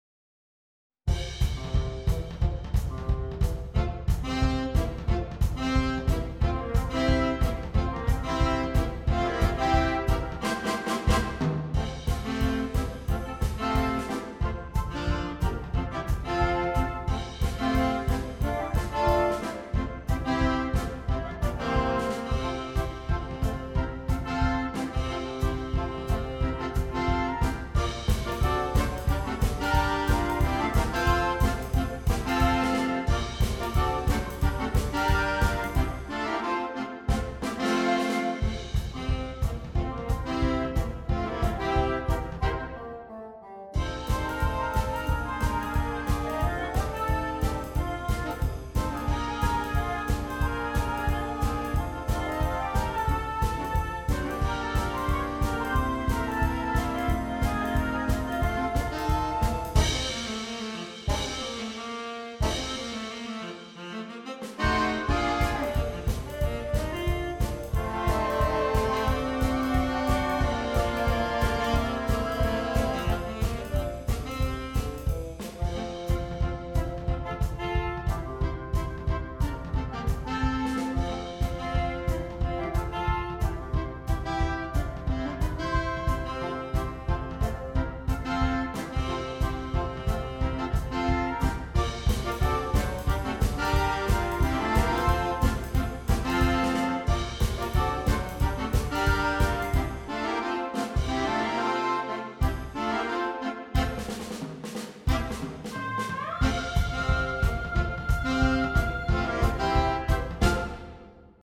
Interchangeable Woodwind Ensemble
This jump swing version